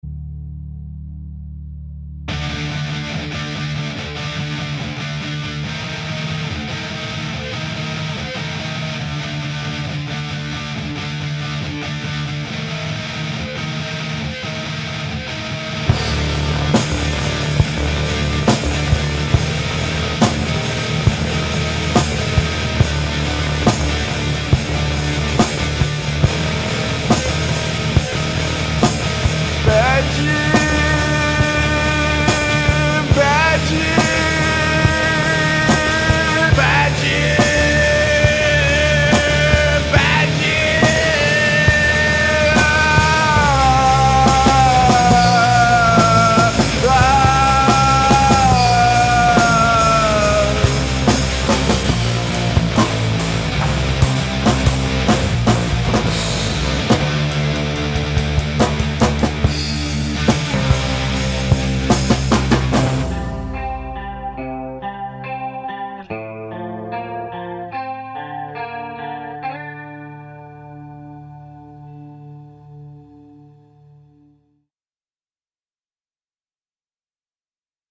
I certainly got more separation now once the drums distortion was out of the way.
We can hear the drum better, but man, it sounds like it's all room and no direct mics..
In general you don't have any dynamics in your tracks, I suspect it could be an abuse of compression or some too fast attack.. or is it that you mixed the overhead and room mic too hot ??
As with this mix I can't discern any bass notes..